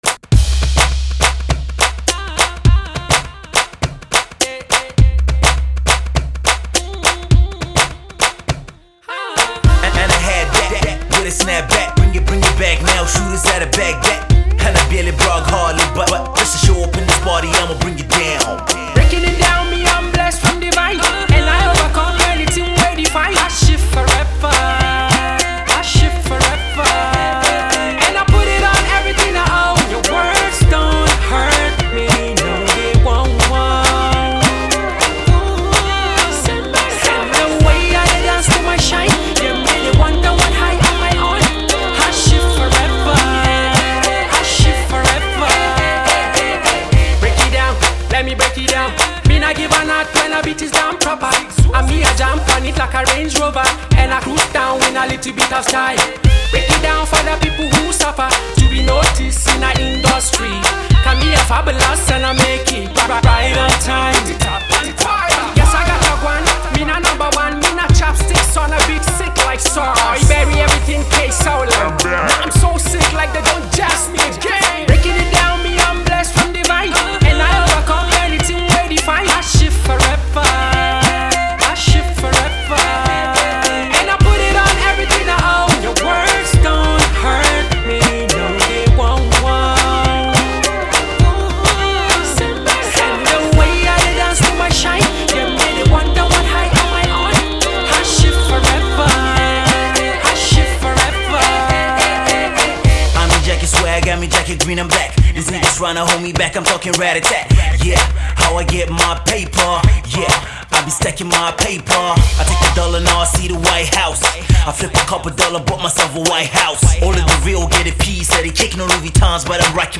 Yummy production and these rising acts